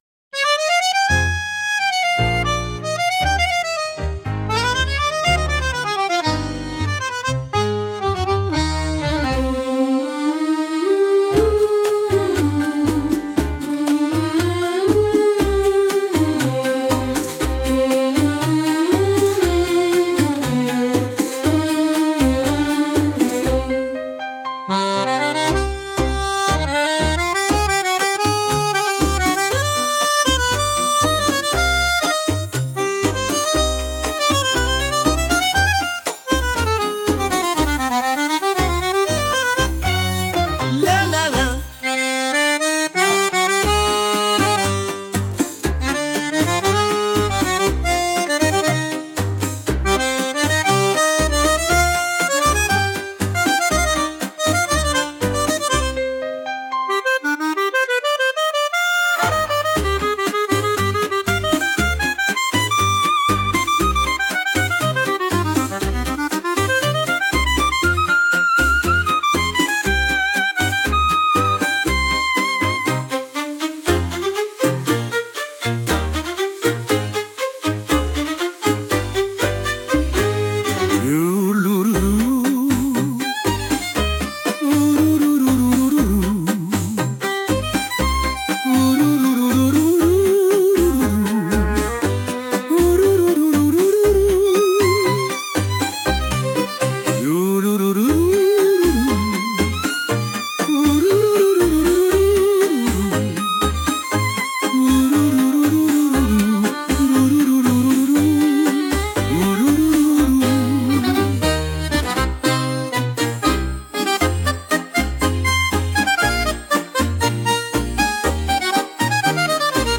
With Vocals / 歌あり
タンゴ特有の情熱はそのままに、角の取れたまろやかな曲調が特徴です。